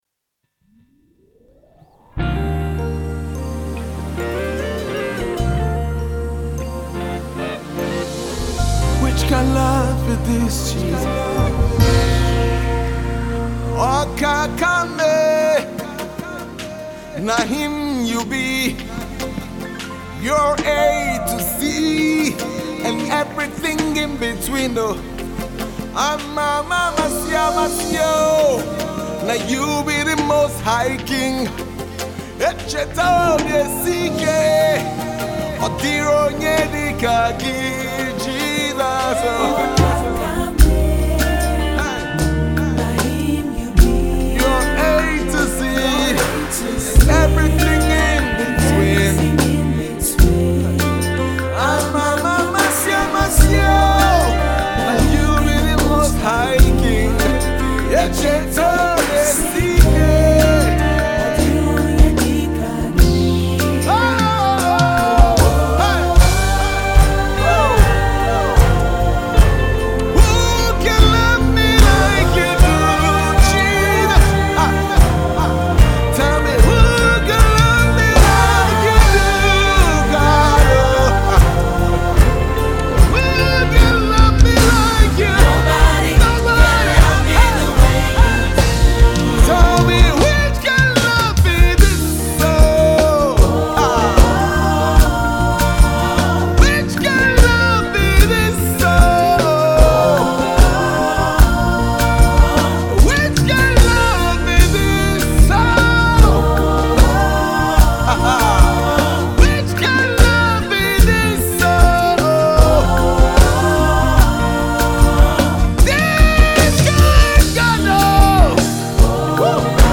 soul lifting praise song